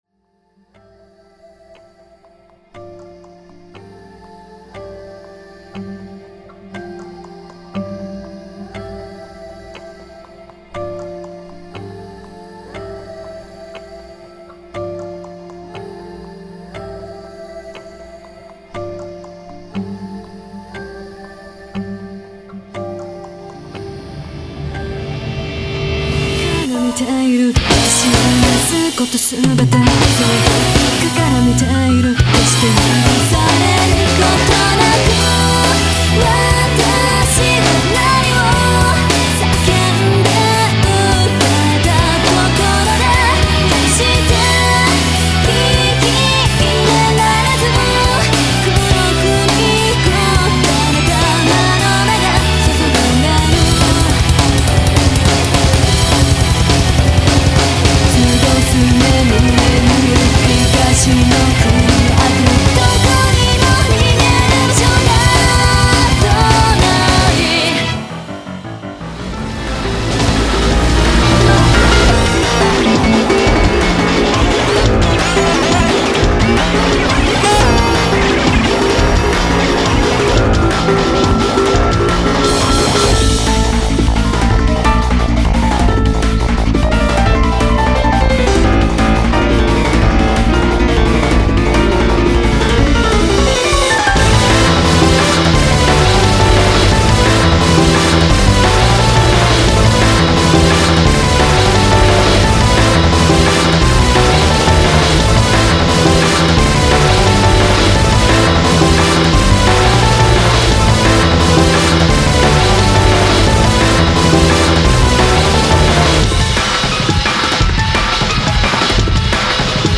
東方マルチジャンルアレンジアルバムです。
クロスフェード(Bad)！